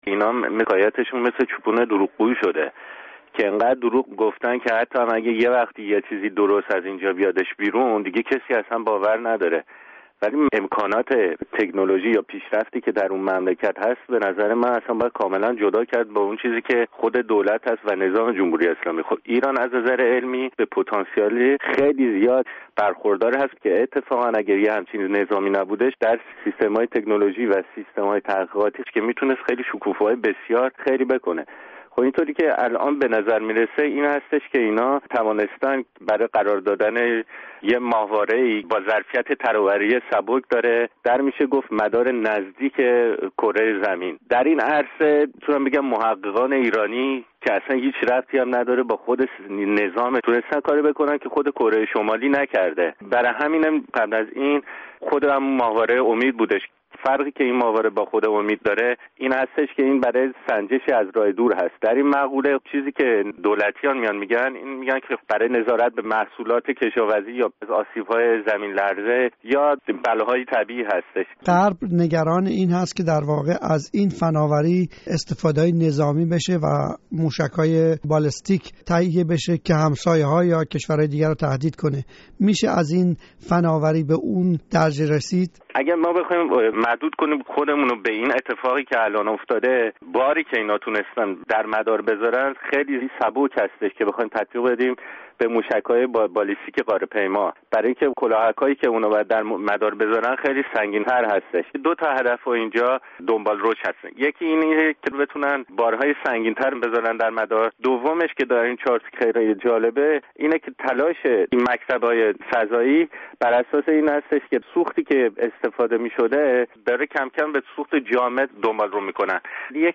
اهمیت پرتاب ماهواره رصد در گفت‌وگو با پژوهشگر ایرانی عضو ناسا